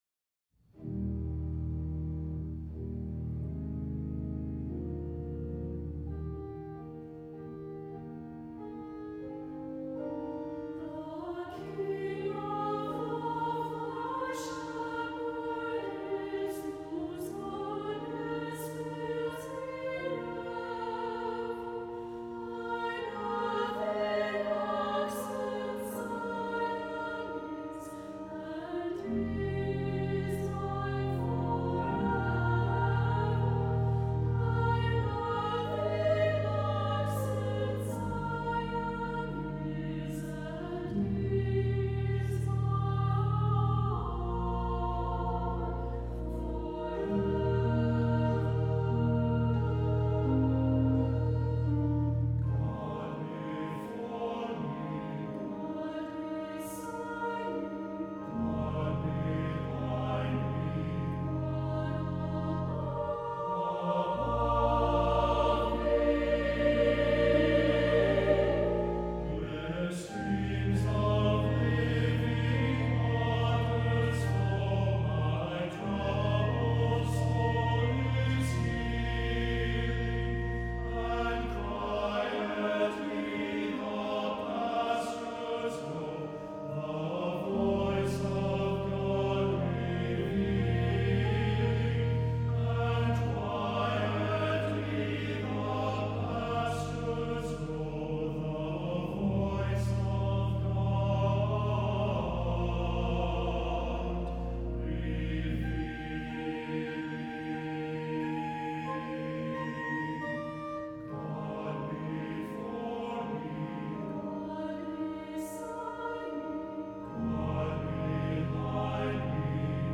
for Two Tenors or Two Treble Voices and Organ (2008)
for SATB Chorus and Organ (2009)